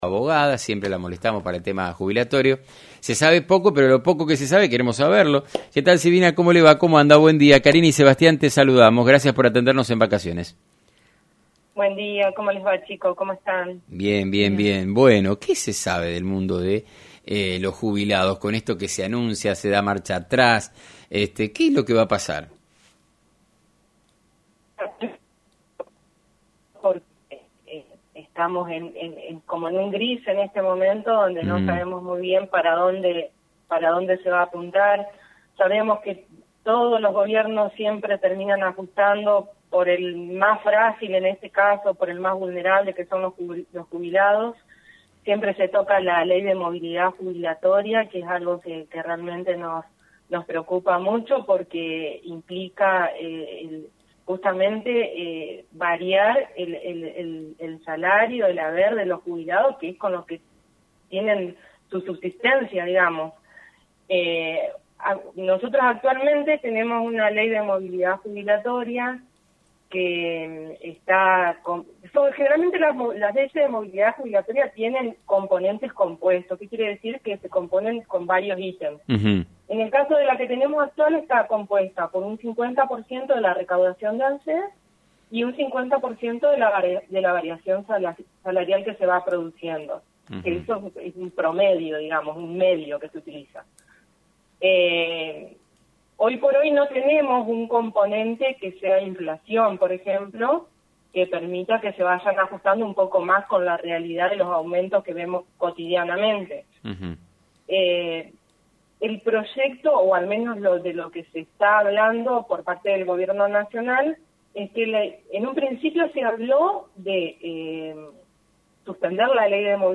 compartió información relevante durante una entrevista por FM90.3.